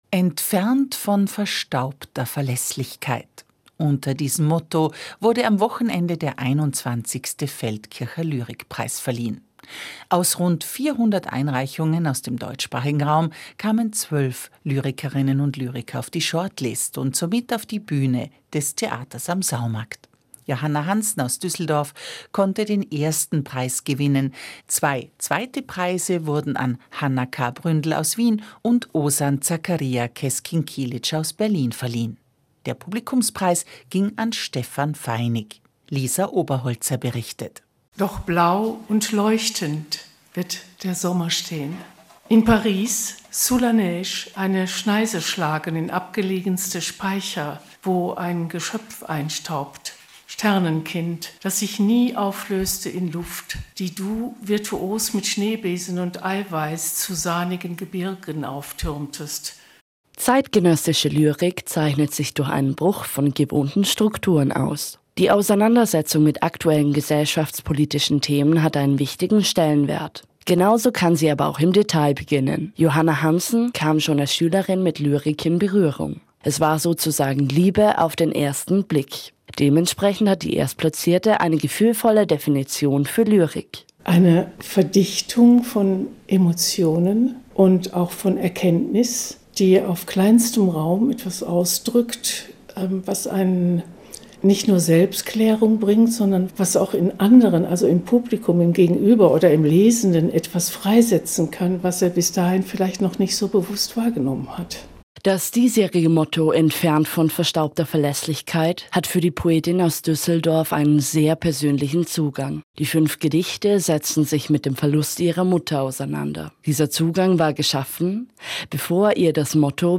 Interview
lyrikpreis-interview-audio-2024.mp3